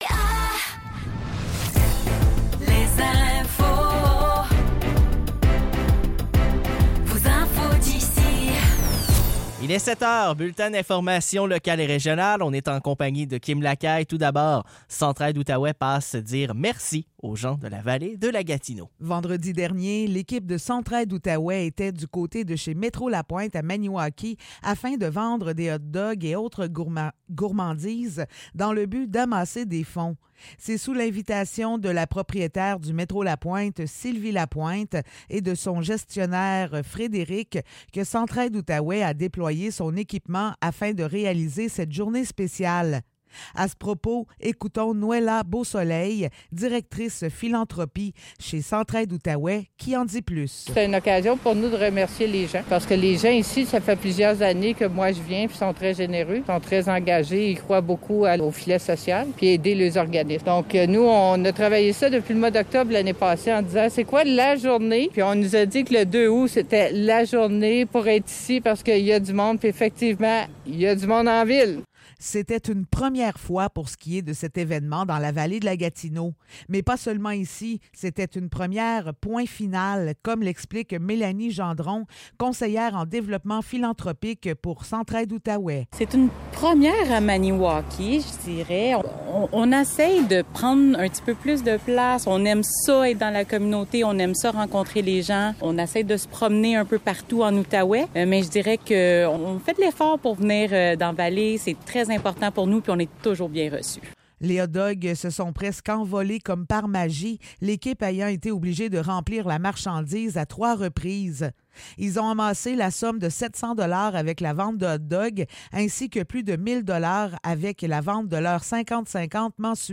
Nouvelles locales - 5 août 2024 - 7 h